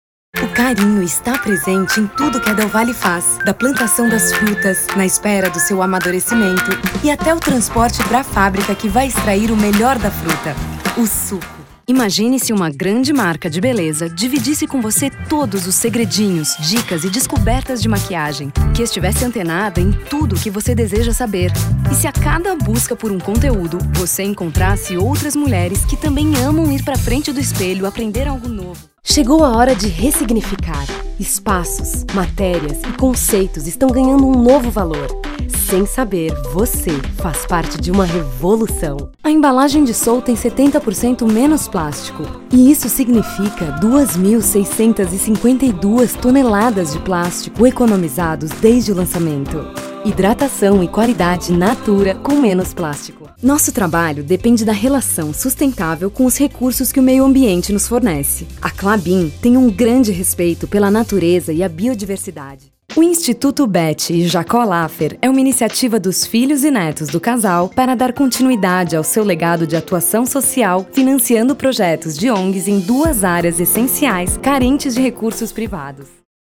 Feminino
Locução Institucional